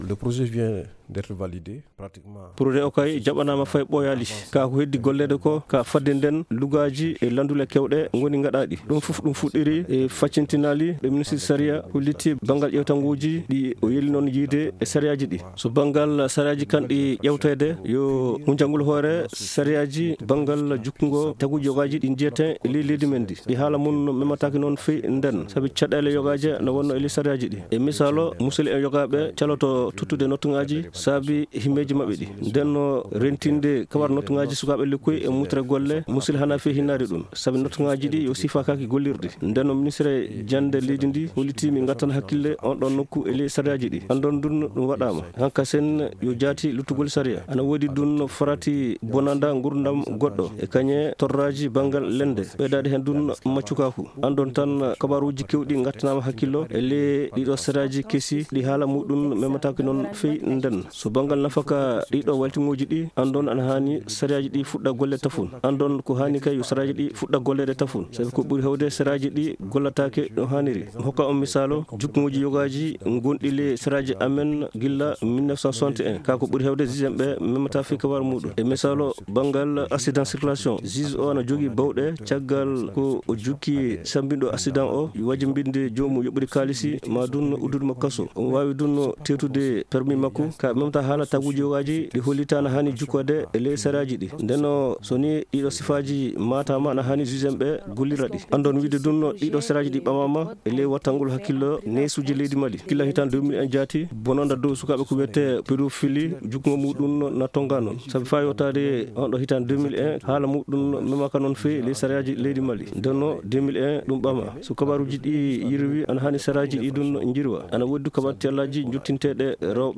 Christian Idrissa Diassana, Magistrat et Président de la chambre criminelle à la Cour Suprême du Mali revient sur ces textes.